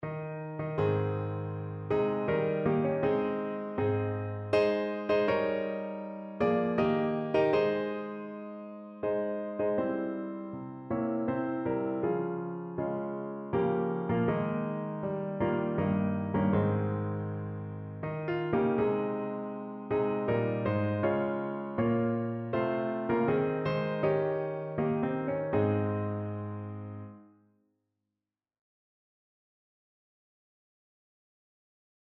Evangeliumslieder
Notensatz (4 Stimmen gemischt)